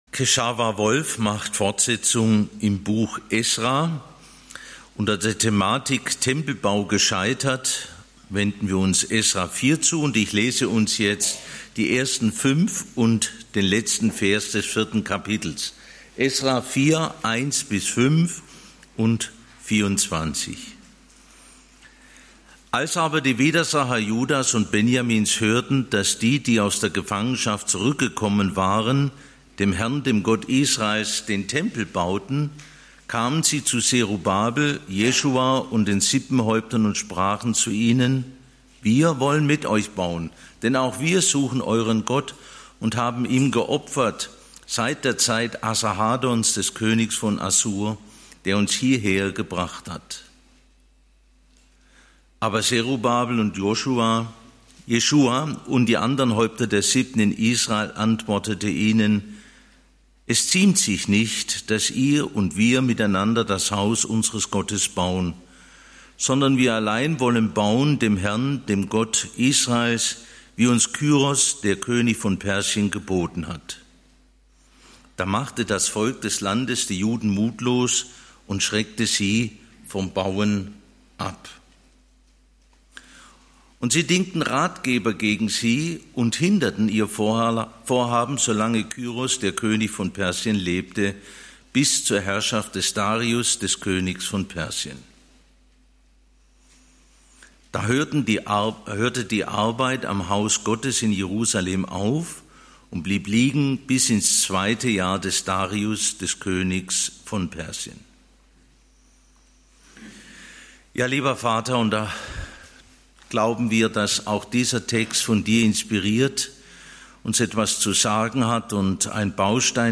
Tempelbau gescheitert? (Esra 4) - Bibelstunde